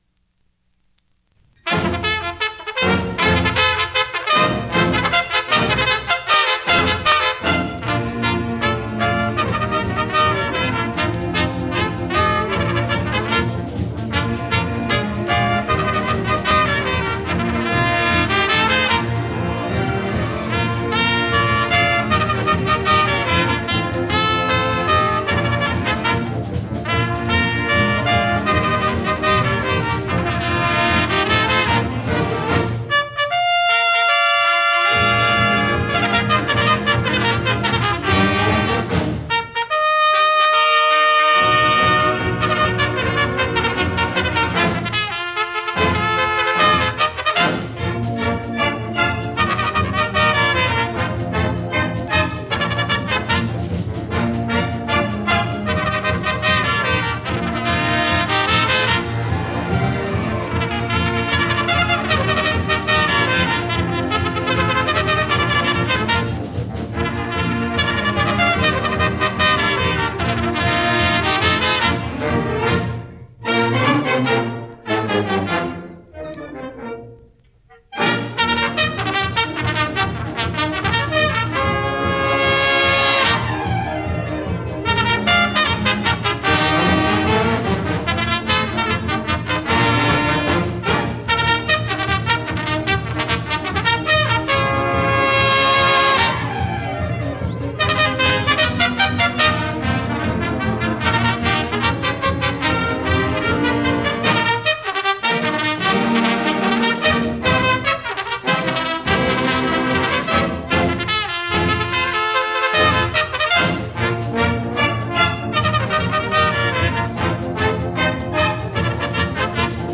“Bugler’s Holiday” from HCI Music Night 1974 by Wind Ensemble.